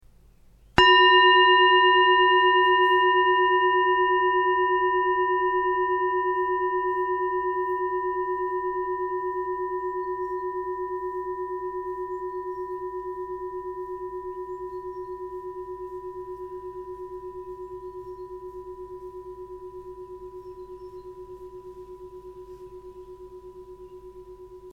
Tibetische Klangschale - HERZSCHALE
Grundton: 371,35 Hz
1. Oberton: 990,57 Hz
M72B-624g-Klangschale.mp3